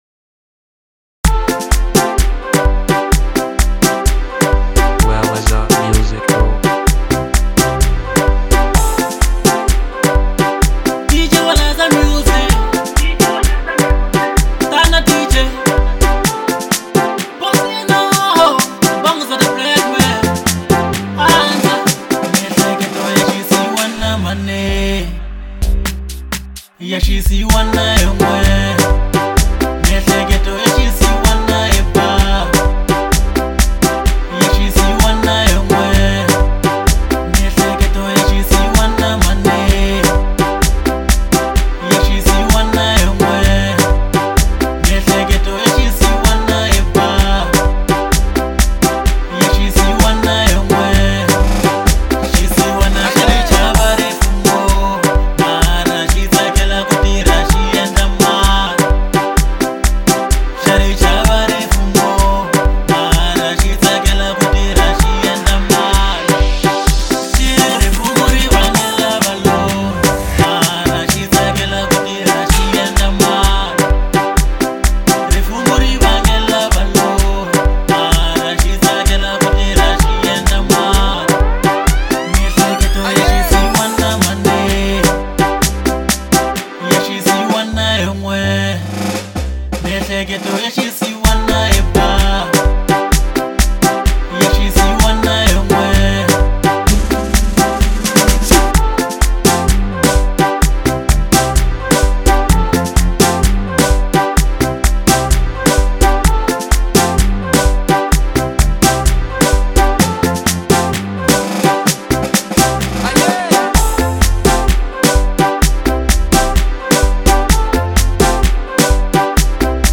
Local House